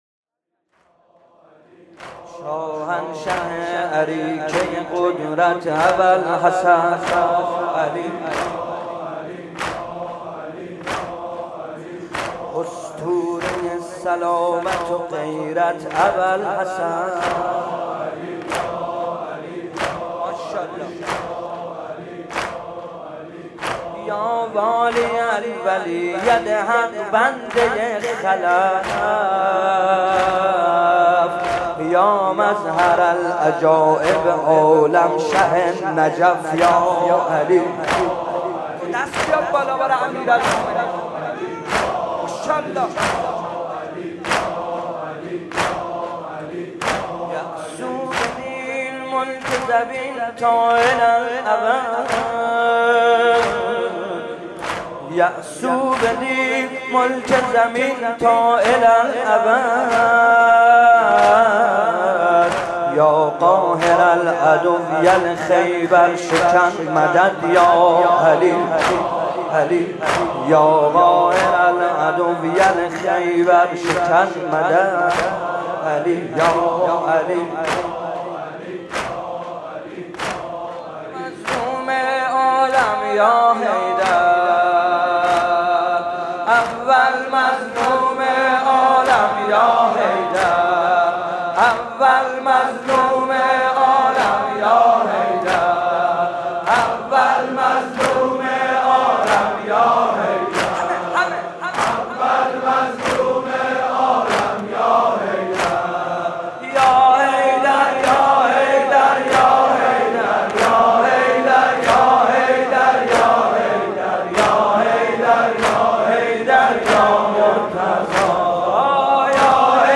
مراسم احیا